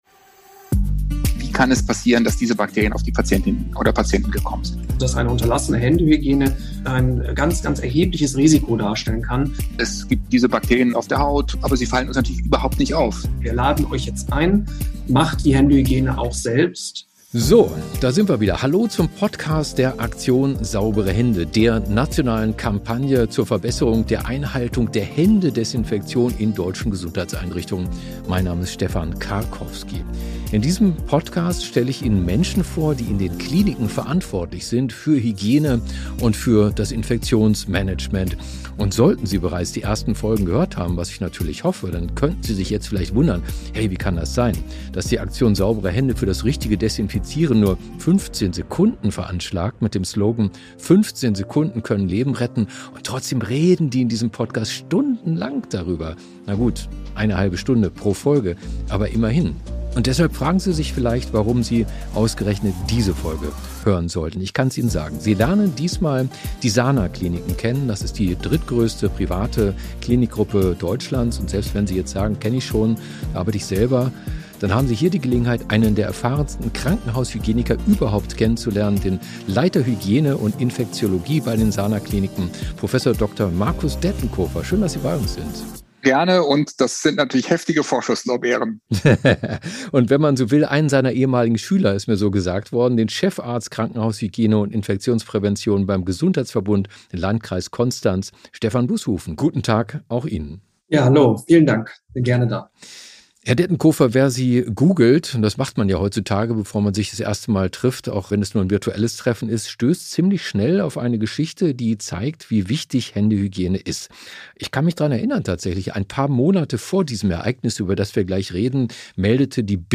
Wie wichtig ist das Infektionsmanagement in Gesundheitseinrichtungen bei Ausbrüchen? Wer könnte diese Fragen treffender beantworten als zwei Klinikhygieniker, die mit ihrer Expertise ganz nah am Geschehen sind